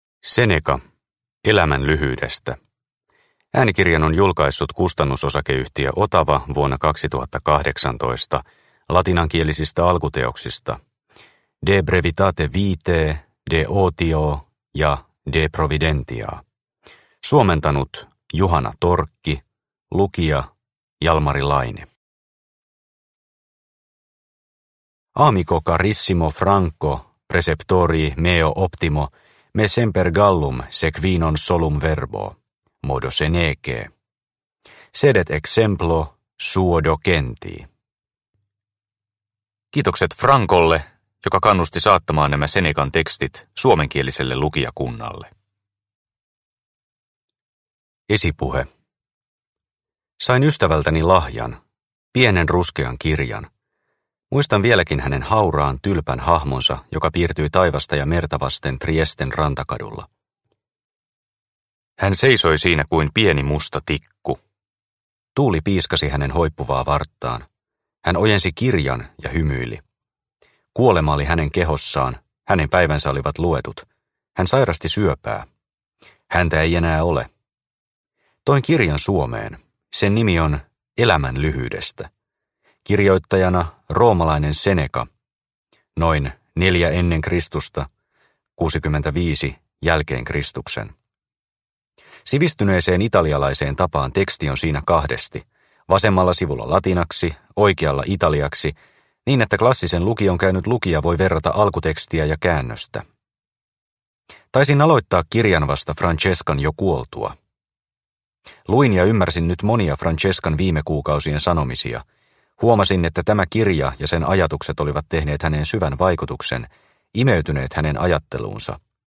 Seneca – Ljudbok – Laddas ner